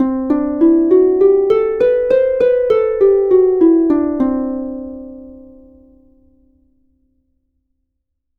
Escala lidia
arpa
sintetizador